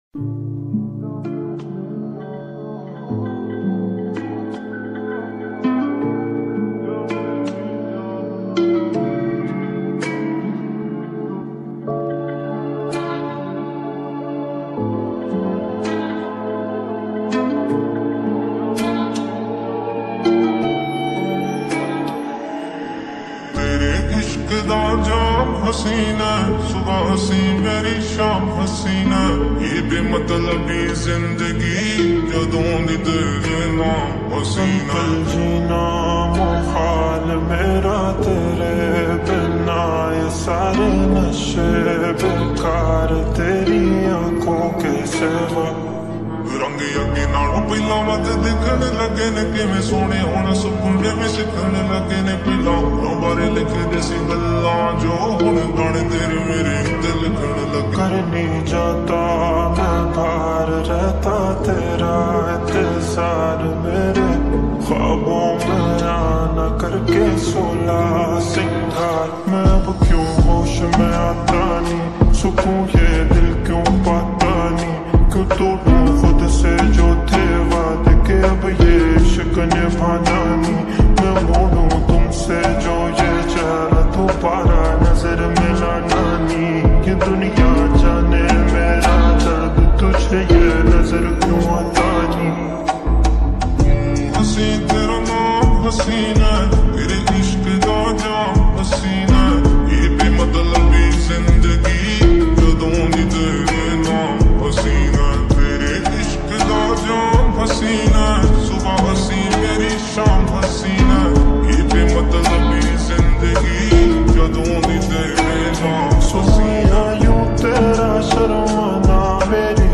Slowed X Reverb